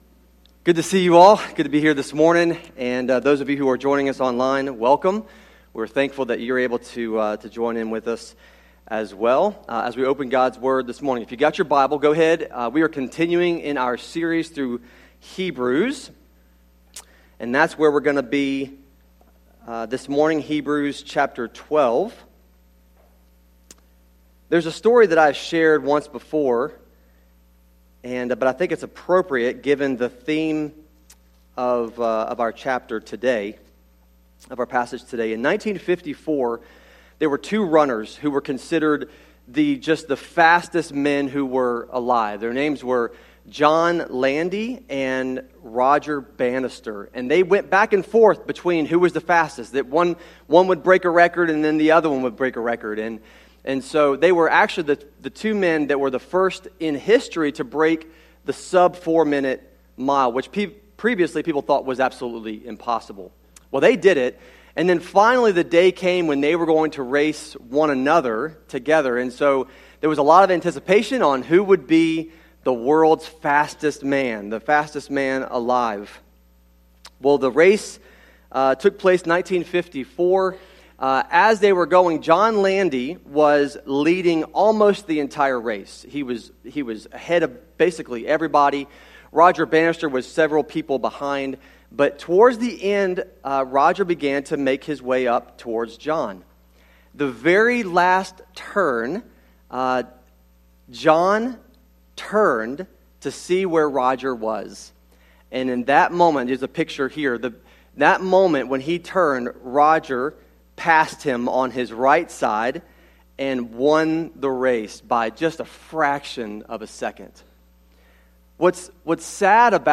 sermon-audio-trimmed-2.mp3